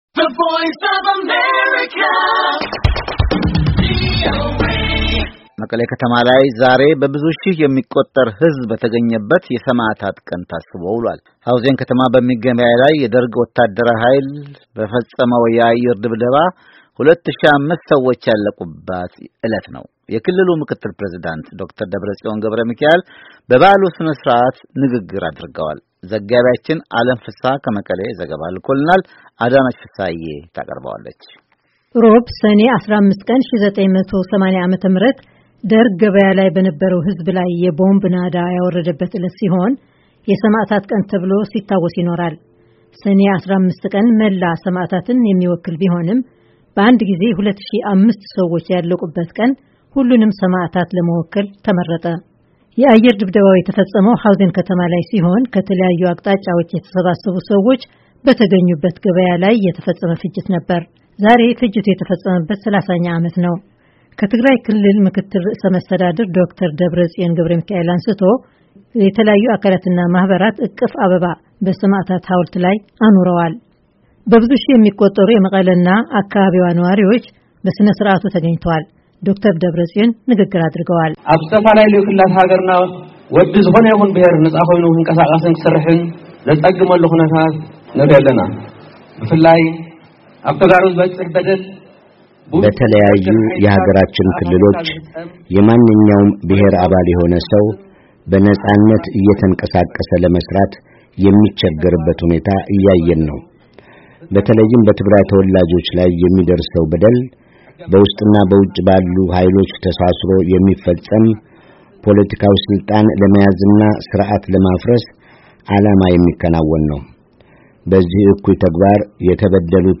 በመቀሌ ከተማ ዛሬ በብዙ ሺህ የሚቆጠር ሕዝብ በተገኘበት የሰማዕታት ቀን ታስቦ ውሏል።
በመቀሌ ከተማ ዛሬ በብዙ ሺህ የሚቆጠር ሕዝብ በተገኘበት የሰማዕታት ቀን ታስቦ ውሏል። ሐውዜን ከተማ በሚገኝ ገበያ ላይ የደርግ ወታደራዊ ኃይል በፈፀመው የአየር ድብደባ 2ሺህ 5 ሰዎች ያለቁባት ዕለት ነው። የክልሉ ምክትል ፕሬዚዳንት ዶክተር ደብረጽዮን ገብረሚካኤል በበዓሉ ሥነ ሥርዓት ንግግር አድርገዋል።